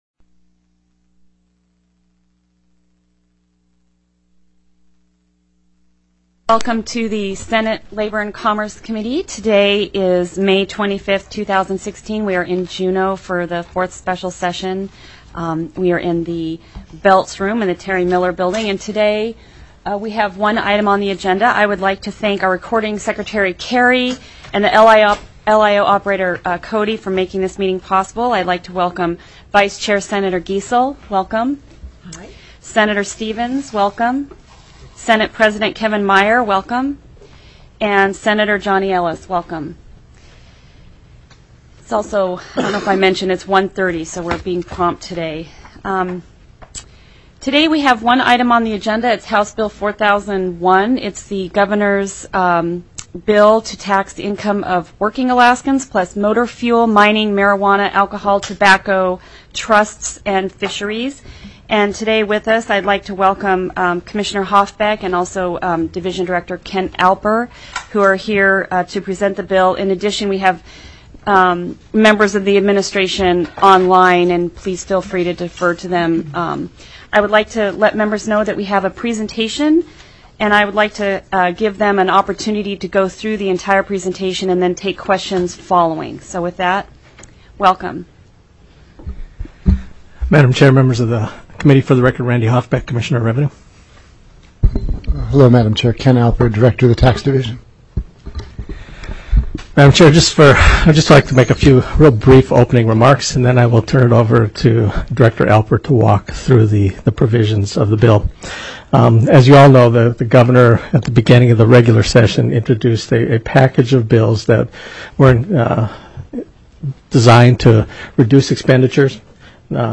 The audio recordings are captured by our records offices as the official record of the meeting and will have more accurate timestamps.
1:31:22 PM RANDALL HOFFBECK, Commissioner, Department of Revenue (DOR), introduced himself.